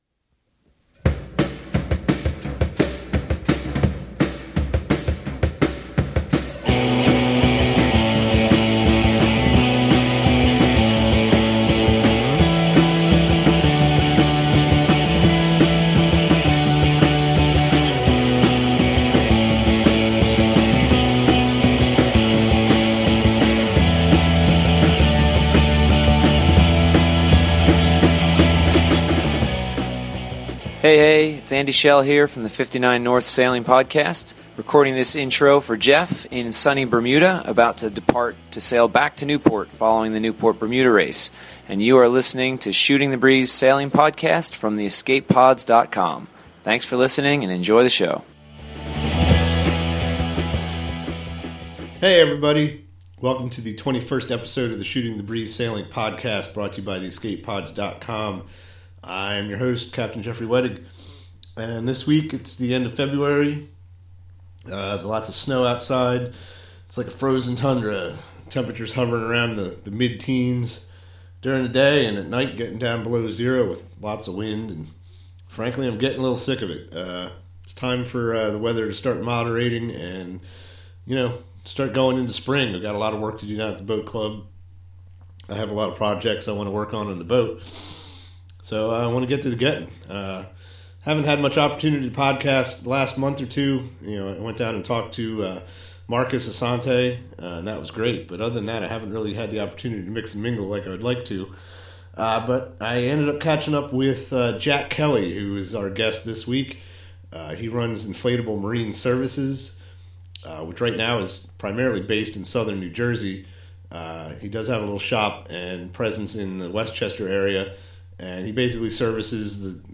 Please excuse the audio quality of this podcast, I am trying to incorporate Skype recording into my podcast interviews, and this time it just flat out didn’t work.